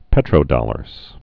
(pĕtrō-dŏlərz)